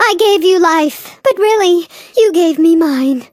flea_ulti_vo_04.ogg